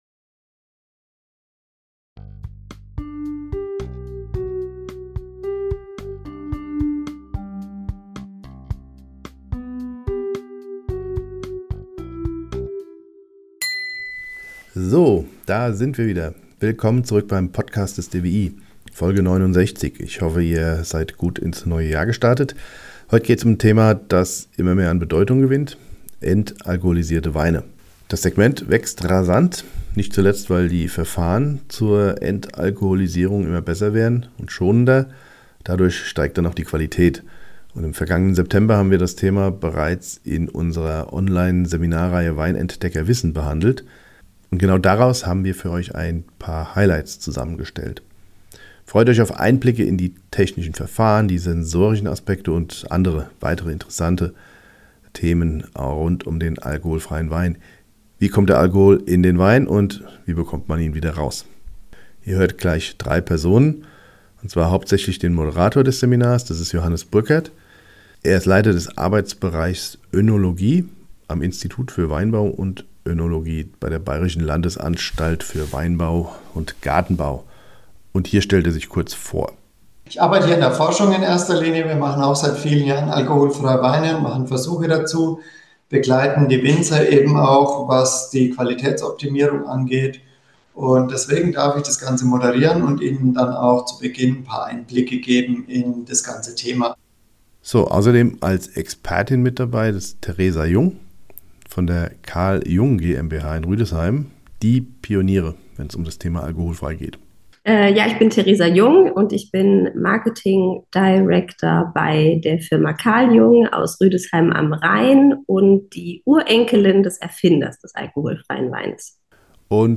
Im vergangenen September haben wir das Thema bereits in unserer Online-Seminarreihe WeinEntdeckerWissen behandelt. Und genau daraus haben wir für euch einige Highlights zusammengestellt. Freut euch auf Einblicke in die technischen Verfahren, die Sensorik und weitere interessante Aspekte. Wie kommt der Alkohol in den Wein – und wie bekommt man ihn wieder heraus?